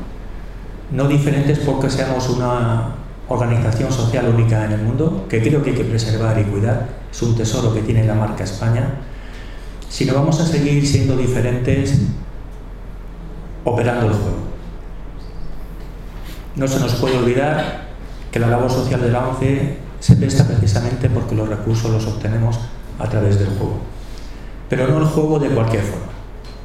Una jornada de HISPACOOP sobre juego responsable en la sede de la ONCE repasa los retos que afronta este compromiso y acoge la presentación del último proyecto de investigación sobre esta materia galardonado por la Organización